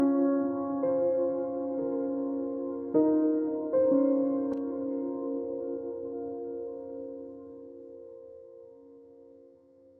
🎵 Background Music
Emotion: longing